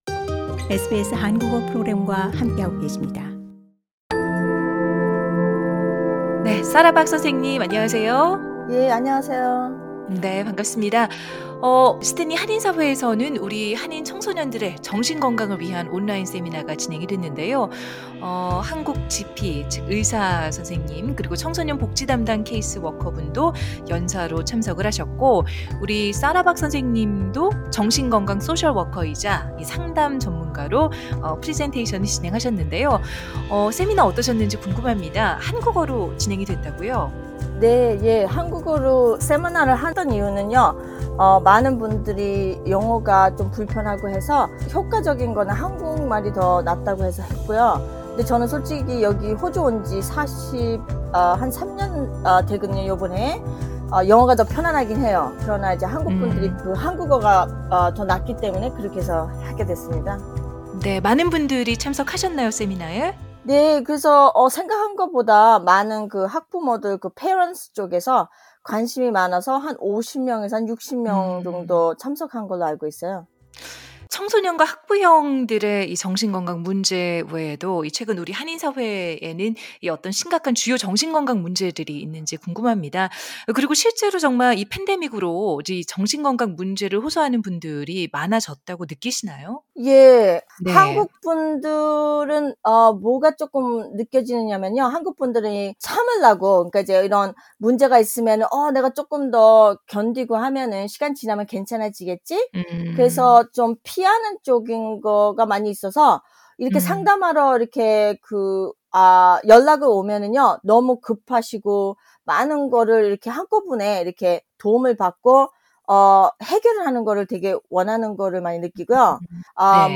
The full interview in Korean is available on the podcast above.